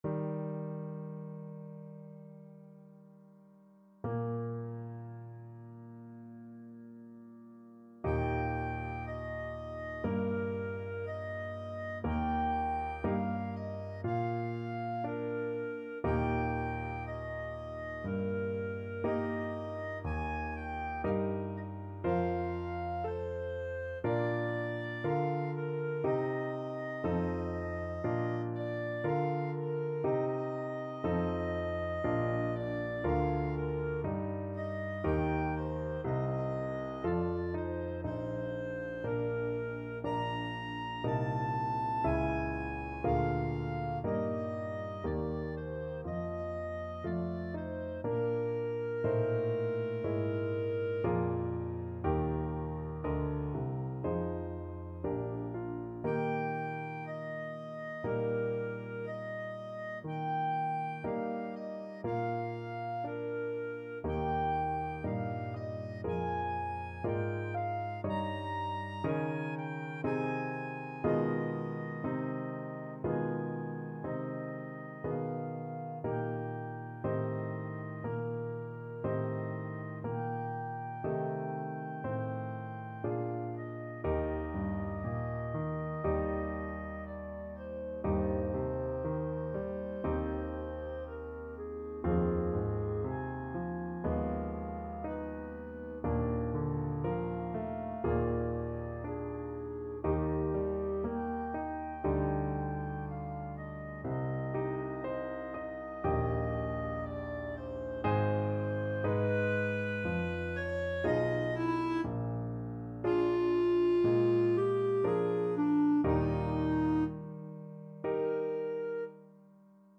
~ = 100 Adagio =c.60
2/4 (View more 2/4 Music)
D5-Bb6
Classical (View more Classical Clarinet Music)